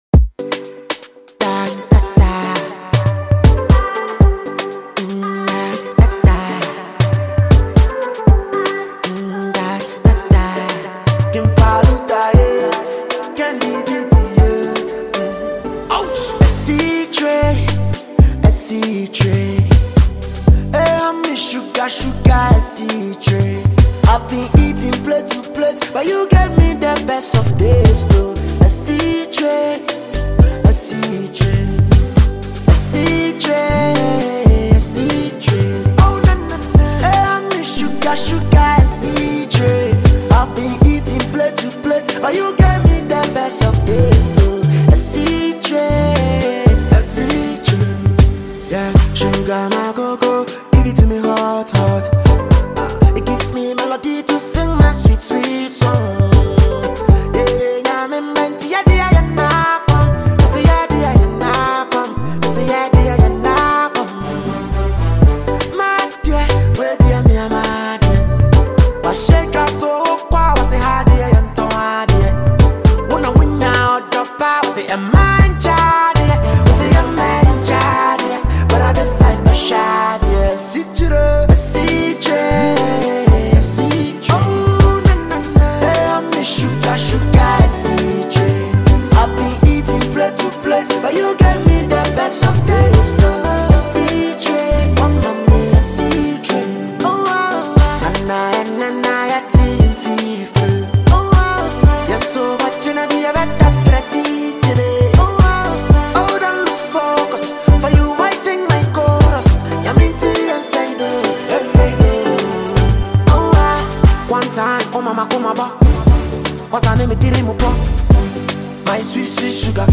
a free afro highlife tune for the public to download mp3